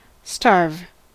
Ääntäminen
IPA : /stɑː(ɹ)v/